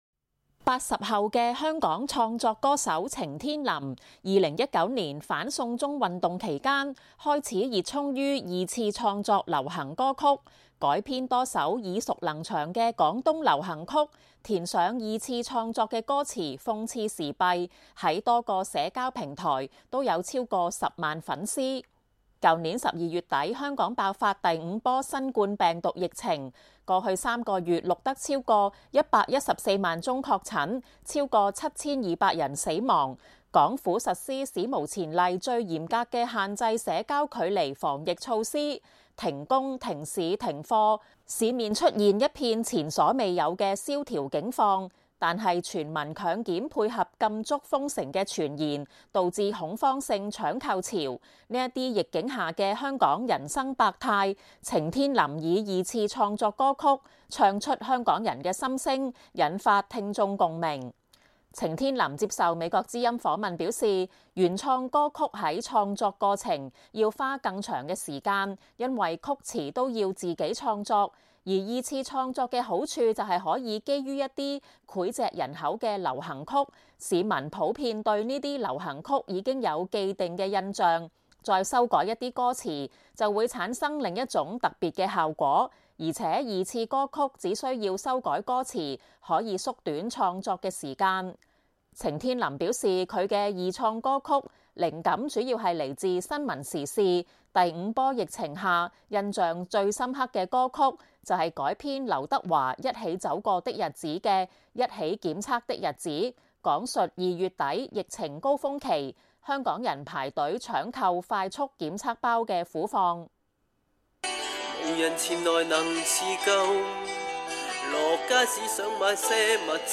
記者問：“即是感覺(中醫)對那些後遺症會有多些幫助﹖”